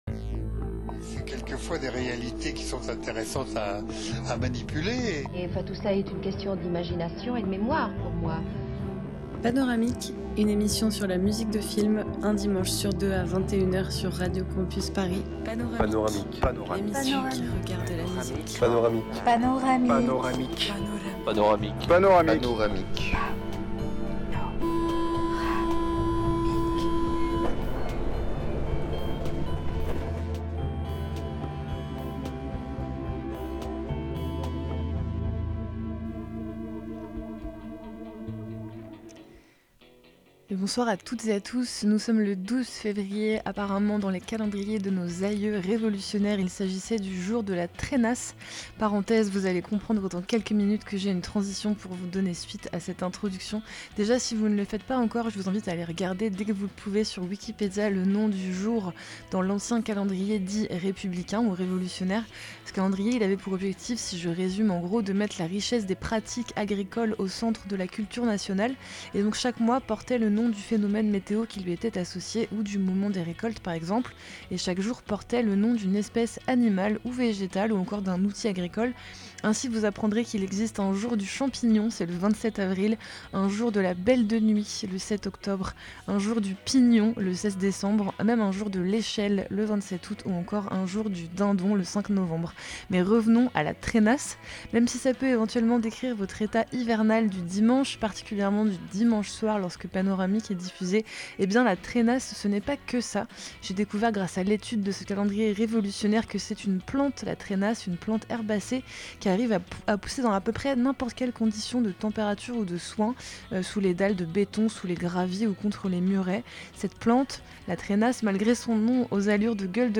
Type Mix